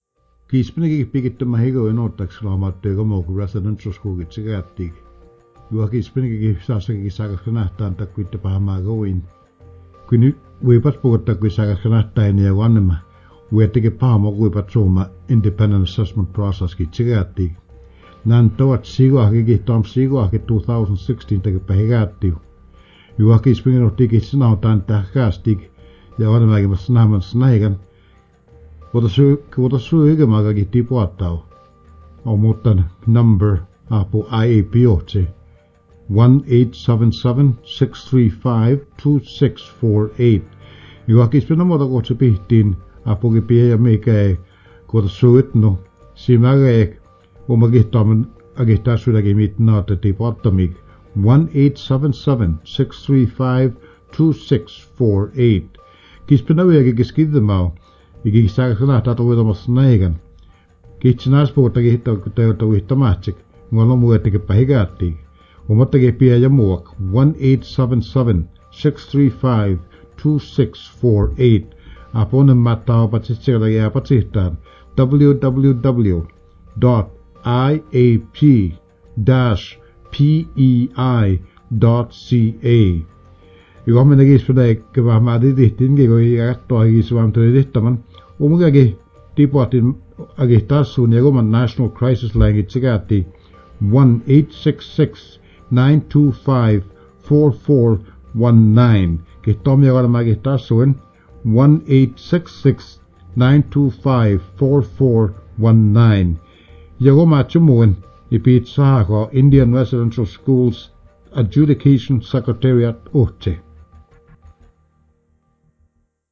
CRI [Ouest du Canada]
Messages d'intérêt public 1